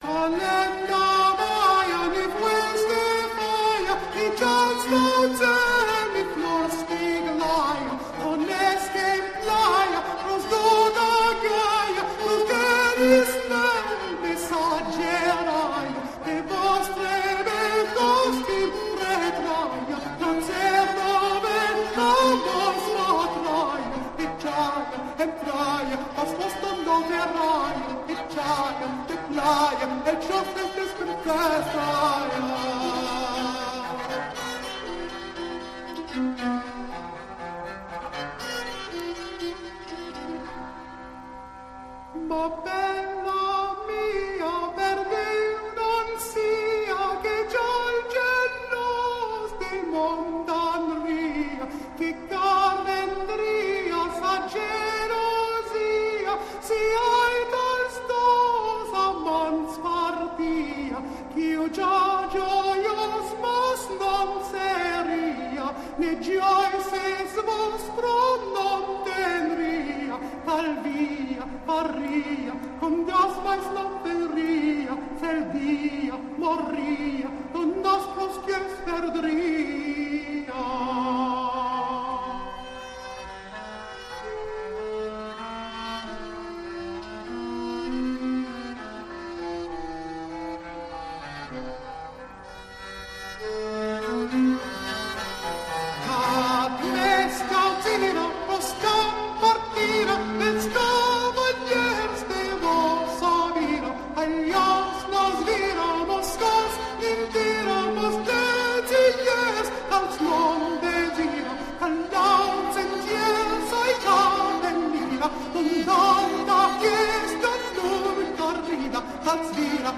tenor
lute